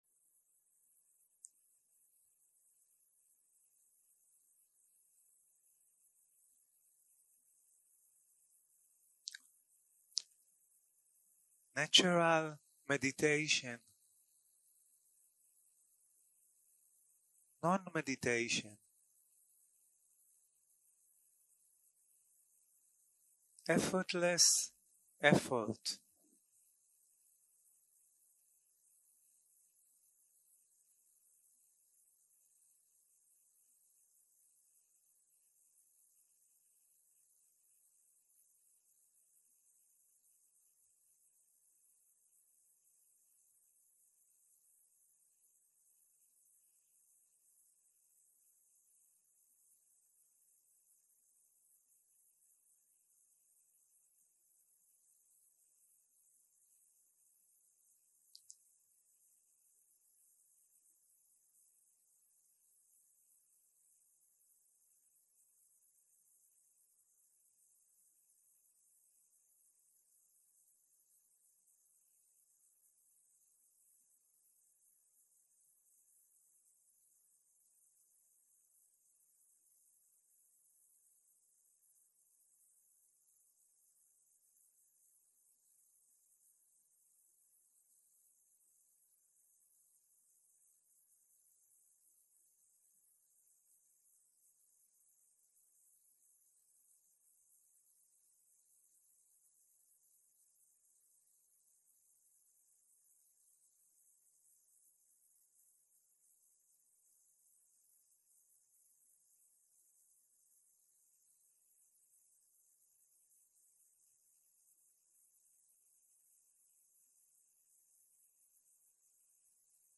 יום 7 - הקלטה 20 - צהרים - מדיטציה מונחית
Dharma type: Guided meditation